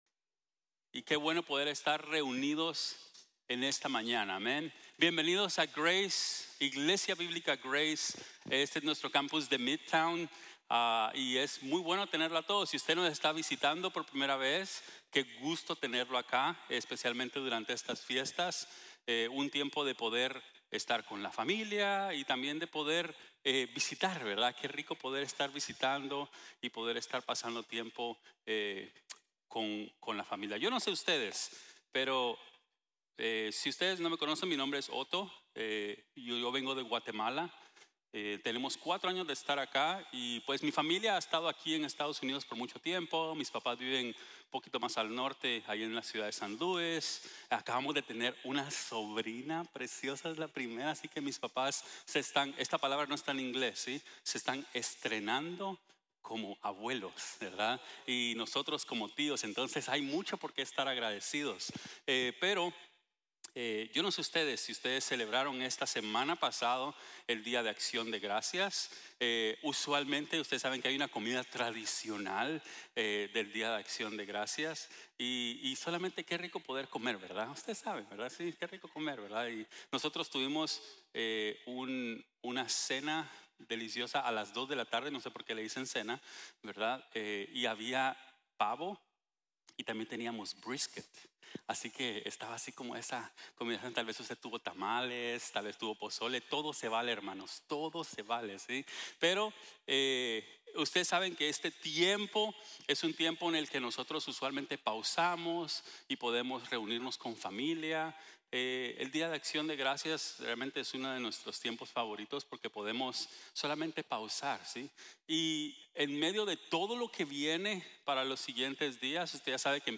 Acción de Gracias en el proceso | Sermón | Grace Bible Church